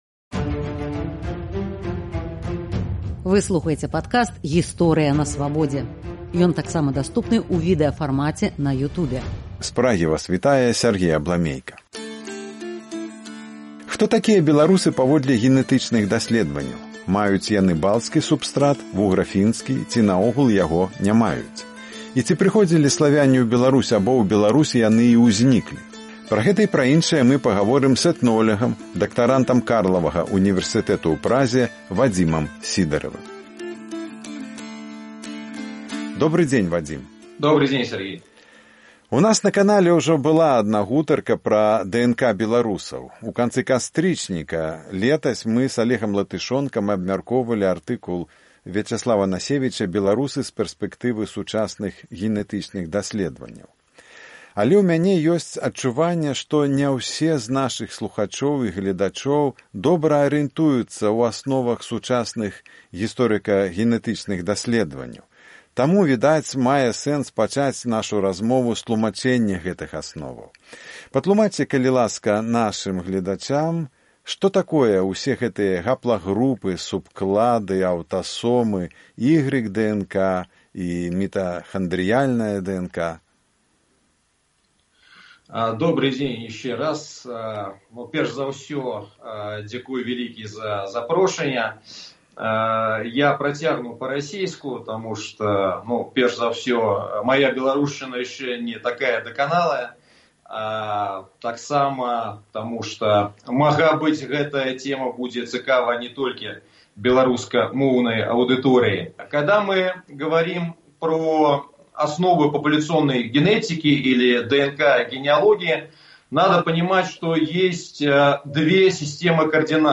Ці прыходзілі славяне ў Беларусь або ў Беларусі яны і ўзьніклі? Пра гэта і пра іншае мы гаворым з этнолягам